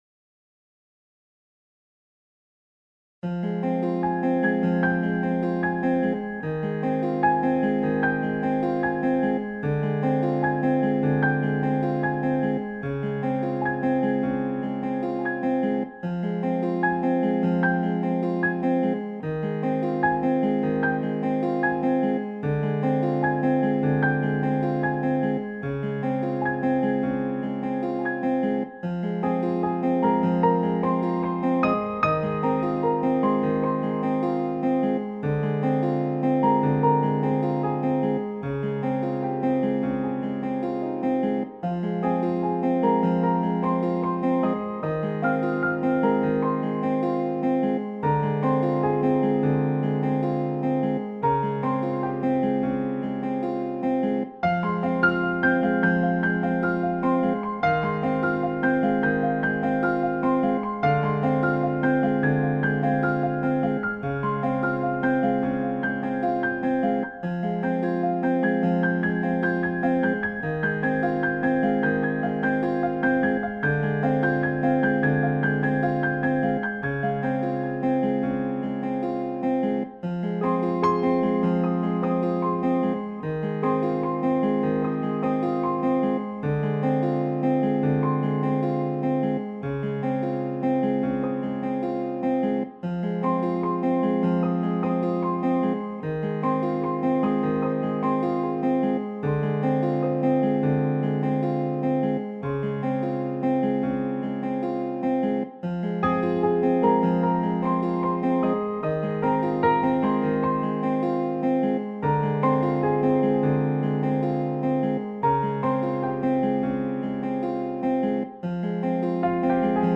guitare - harpe - aerien - folk - melodieux